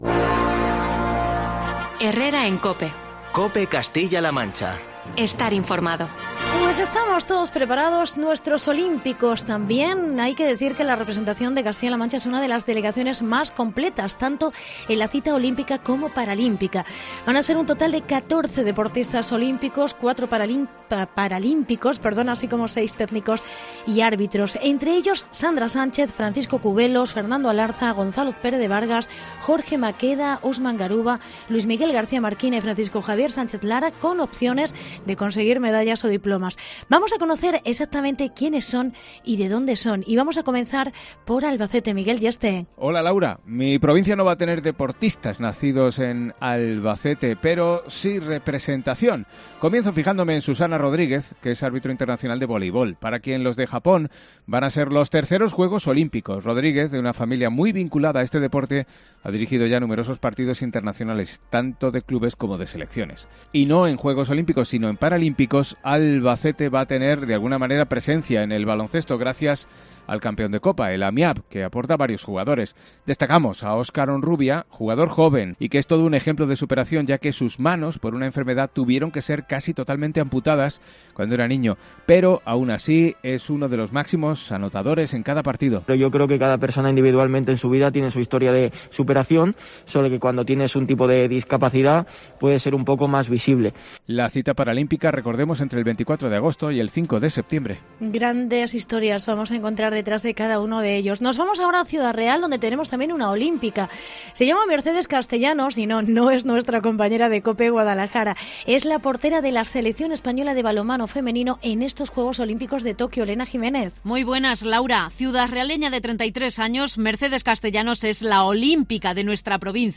Reportaje Olímpicos de CLM